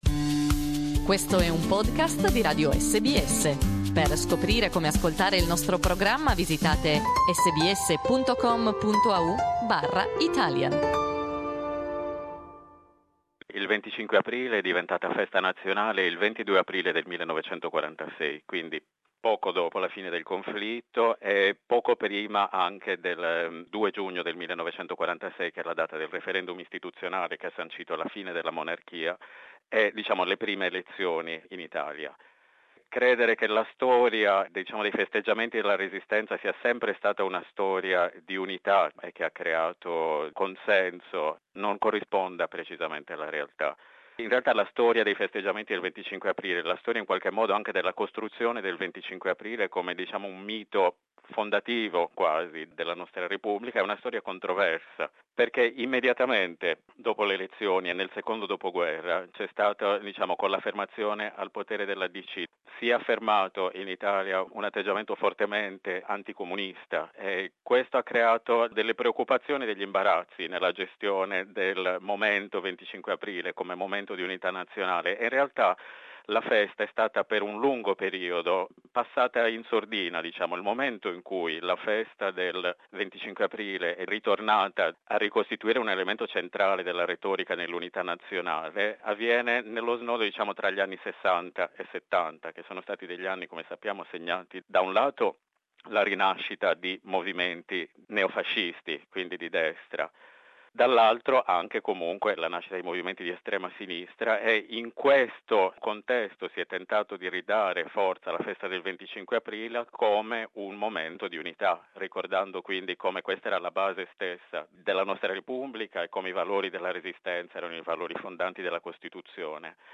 Our interview.